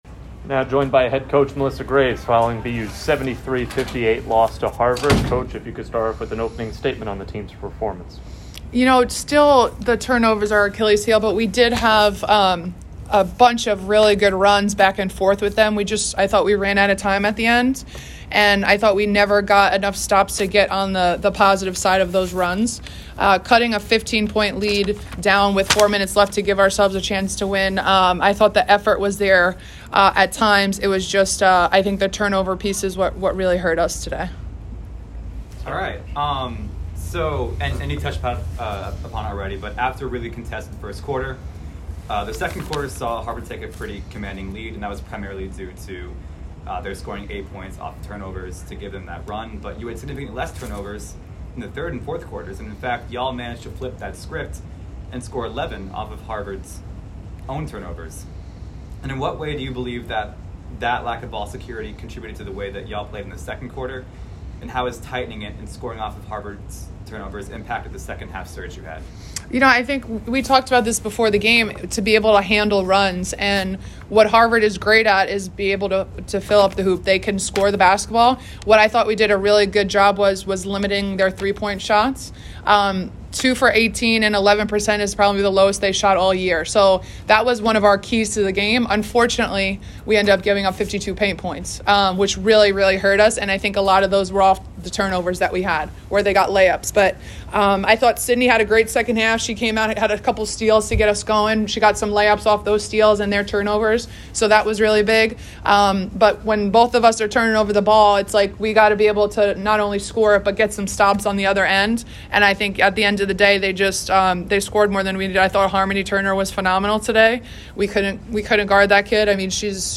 WBB_Harvard_Postgame.mp3